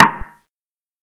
soft-hitfinish.ogg